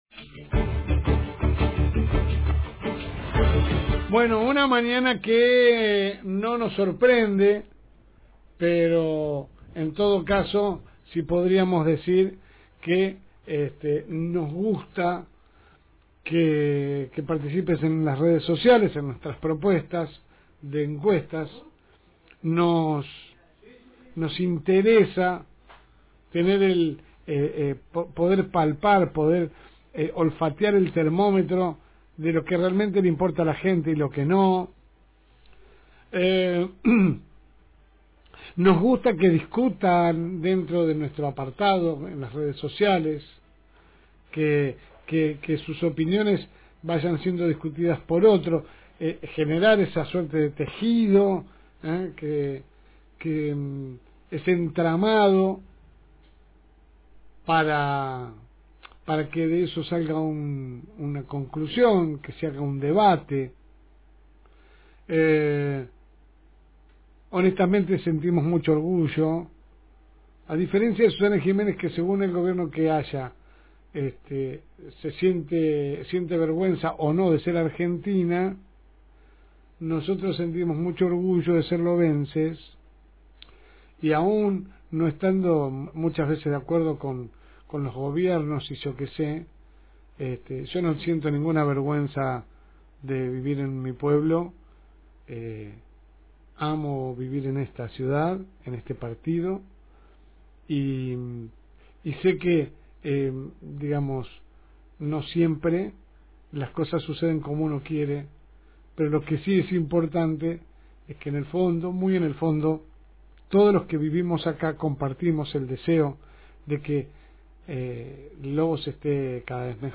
AUDIO – Editorial de la LSM – FM Reencuentro